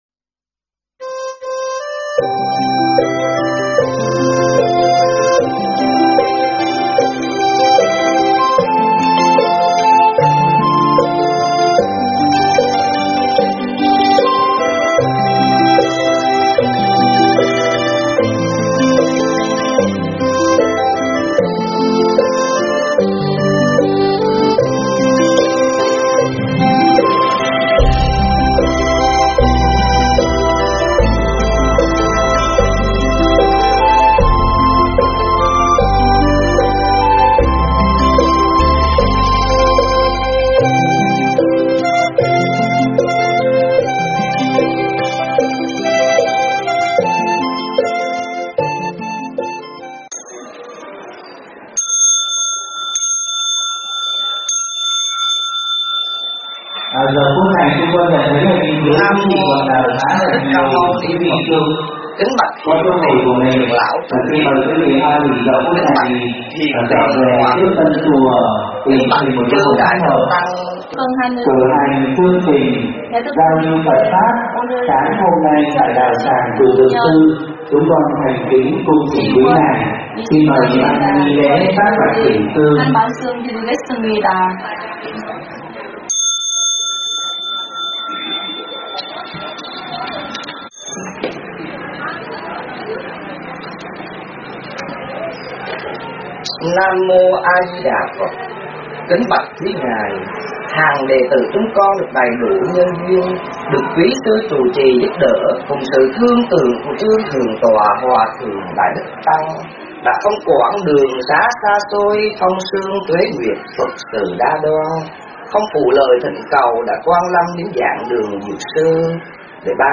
pháp thoại
giảng tại Chùa Dược Sư, Incheon, Hàn Quốc